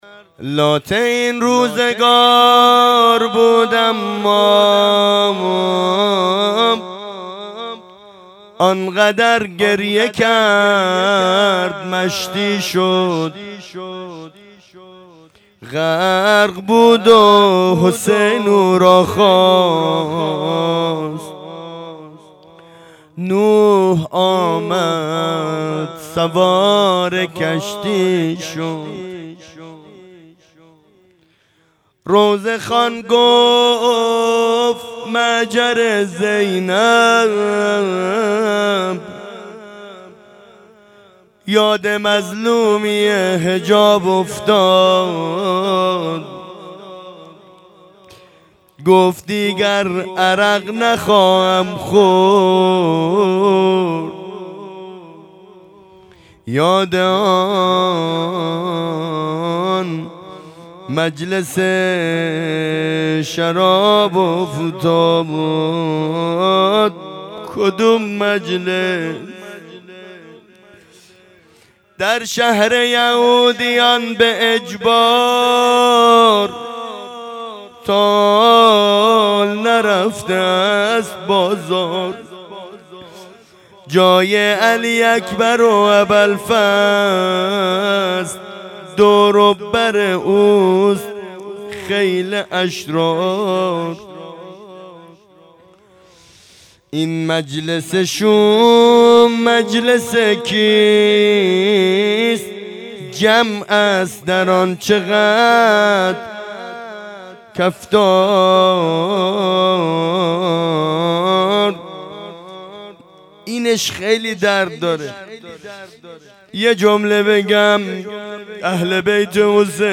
شب چهارم محرم-یکشنبه 8-4-1404
زمزمه پایانی